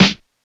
Snares
Snare (30).wav